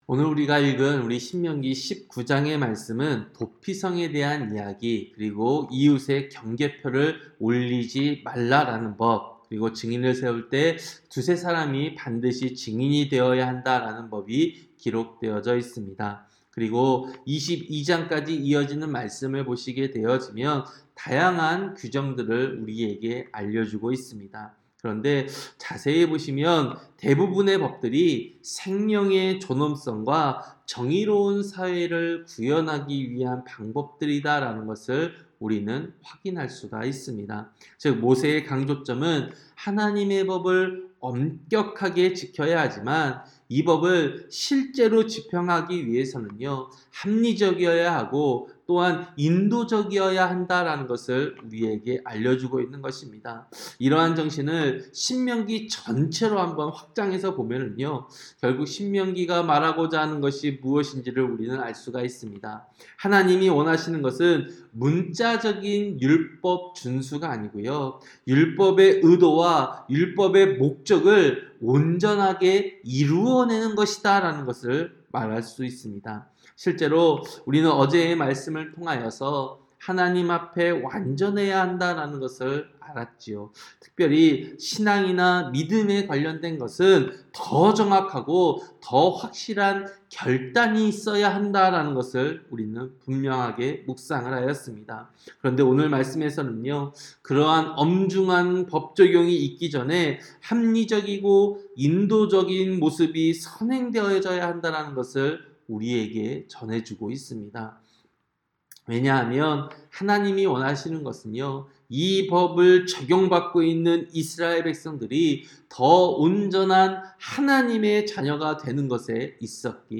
새벽설교-신명기 19장